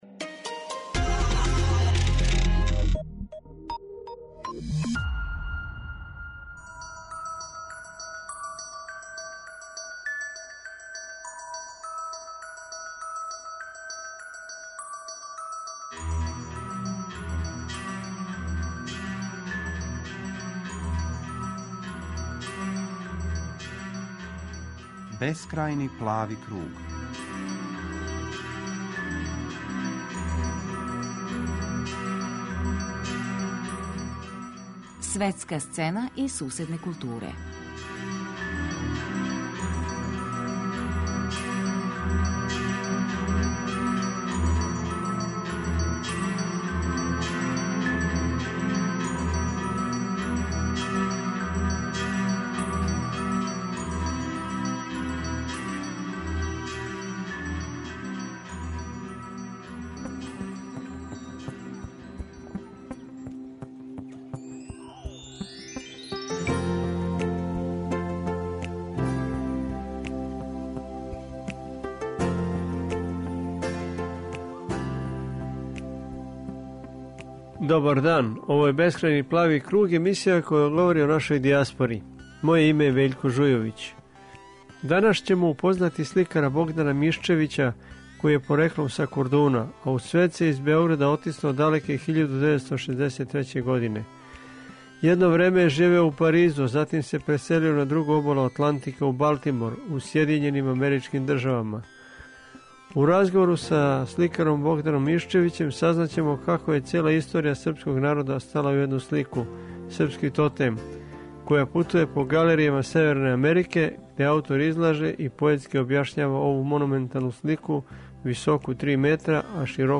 Разговарамо са сликаром